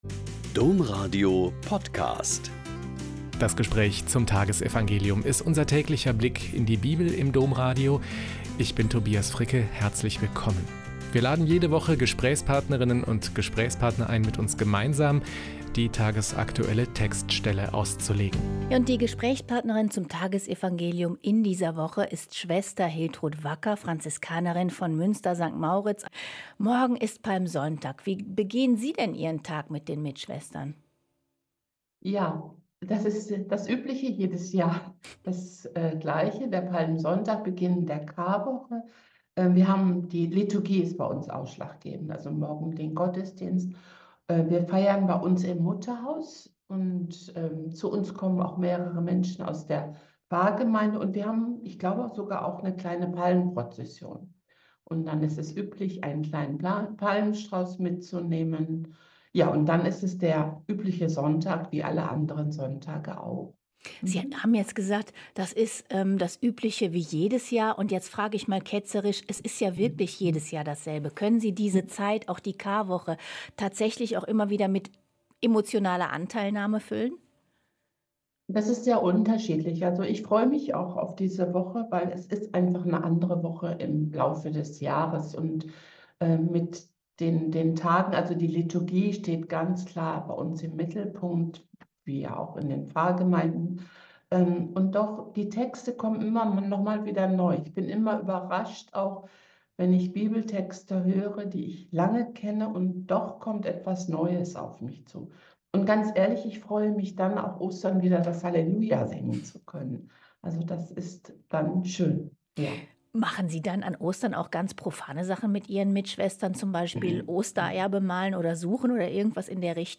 Joh 11,45-57 - Gespräch